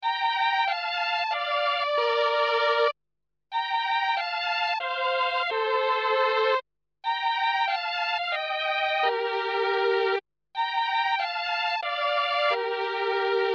MELLOTRON 400SM
Le son qui ressort des premiers modèle est très particulier, il sonne un peu comme dans les musiques qui accompagnaient les films muets, c'est assez sale.
Peu fiable, le mecanisme du Mellotron est conçu à partir de bandes sonores préenregistrées qui se déclenchent à l'appuie d'une touche.
Strings
mellotron_strings.mp3